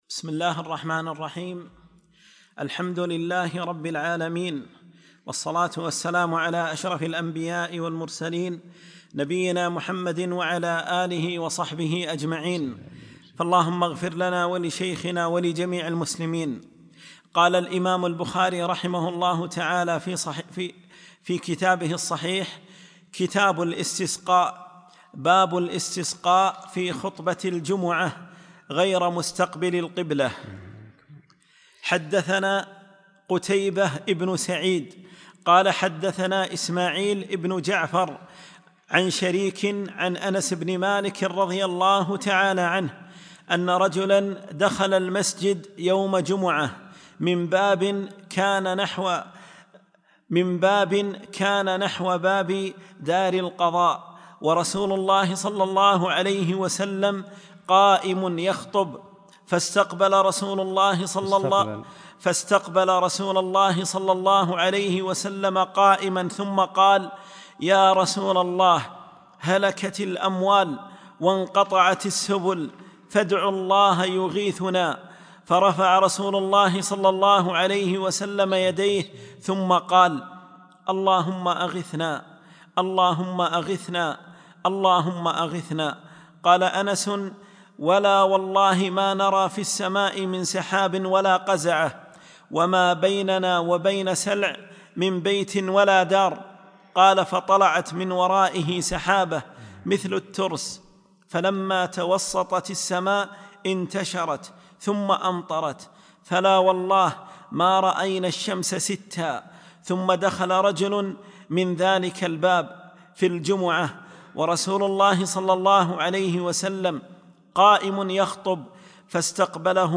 2- الدرس الثاني